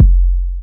pcp_kick18.wav